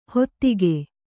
♪ hottige